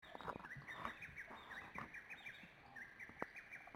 カ ッ コ ウ 属   ホ ト ト ギ ス １　1-08-09
声　　　：特許許可局と聞きなしされる声で鳴く。夜間、飛びながら鳴くことも多い。ピピピピとも鳴く。
鳴き声１
hototogisu01.mp3